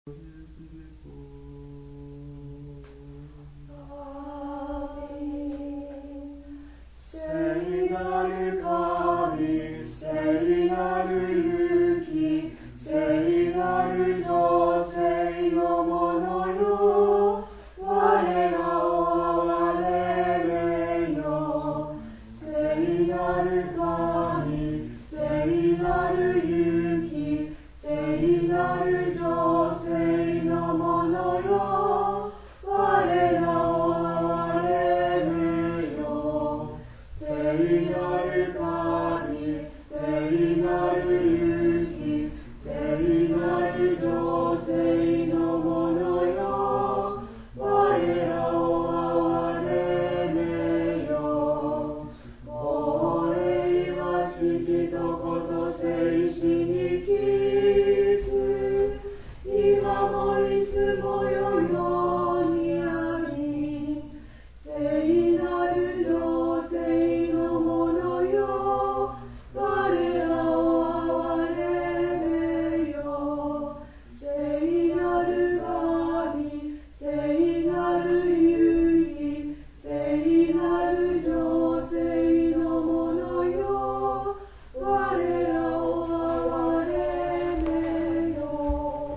新しい聖堂は天井が高く、堂内に木を多用してあるため、柔らかく響きます。
We have been advocating congregational singing, now almost all the attendants participate singing.
○聖体礼儀から　　Live recording at Divine Liturgy on Aug. 21　NEW
ロシア系の教会で、最も一般的に歌われるメロディ。
Trisagion_uni.mp3